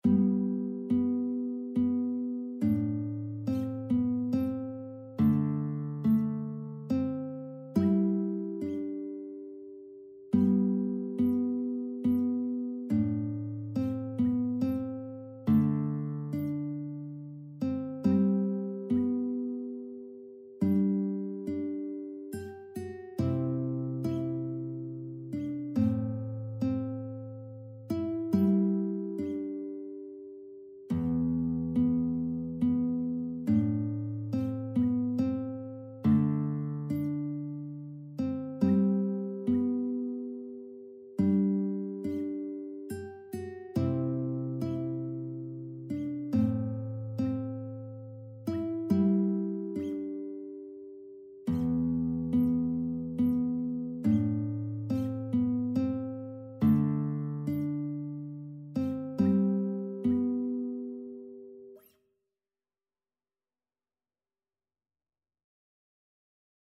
Tradycyjna polska kolęda
w tonacji G-dur opracowana w technice fingerstyle na gitarę.
Instrument Gitara
Gatunek Kolęda